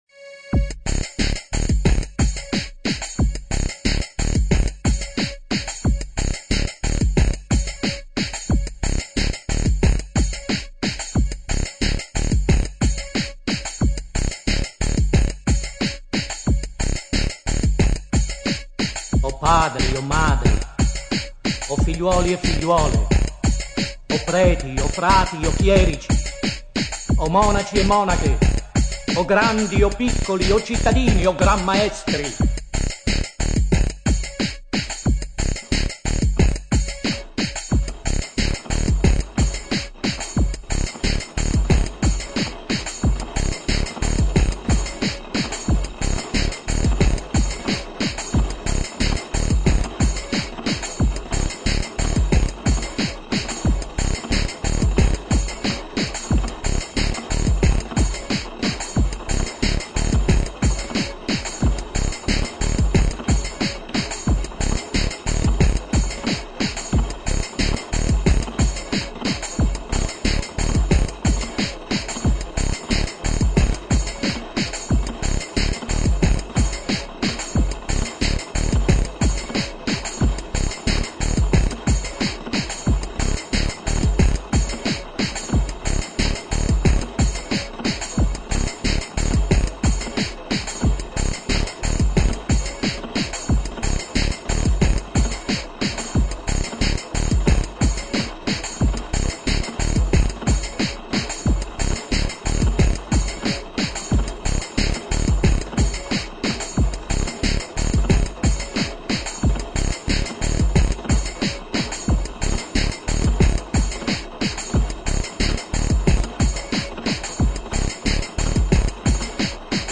Музыка для балета